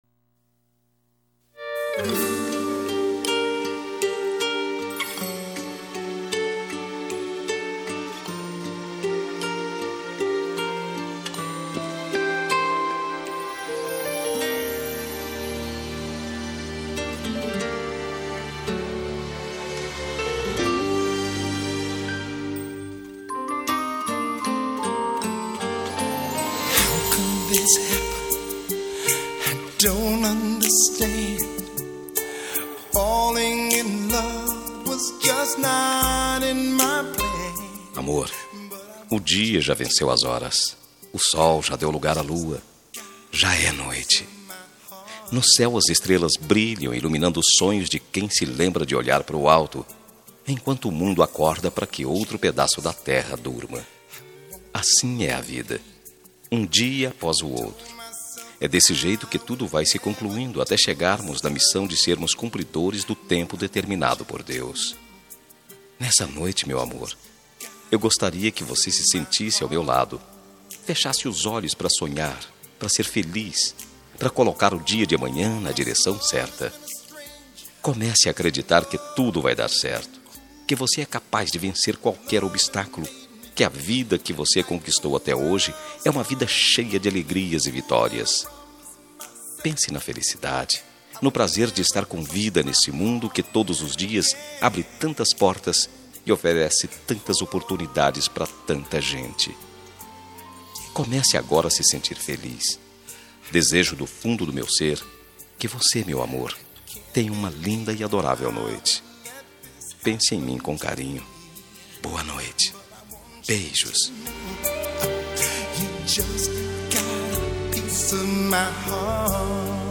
Telemensagem de Boa Noite – Voz Masculina – Cód: 63241 – Romântica